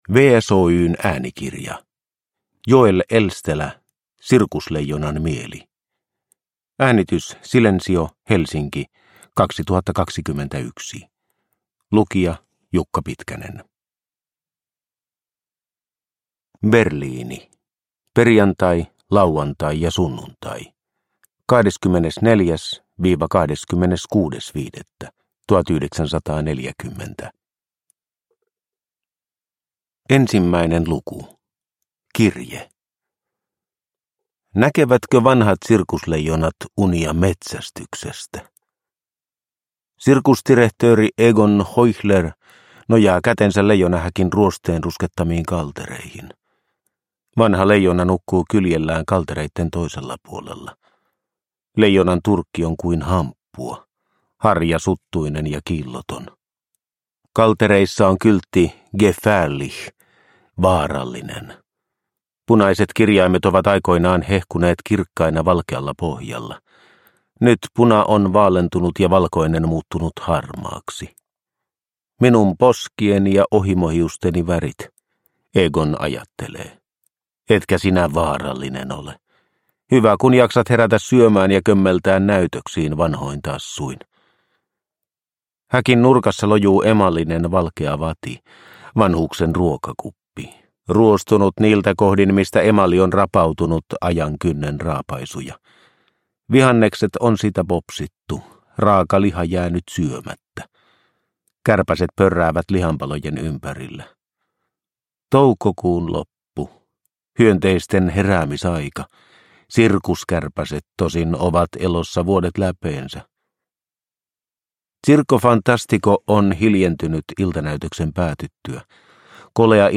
Sirkusleijonan mieli – Ljudbok – Laddas ner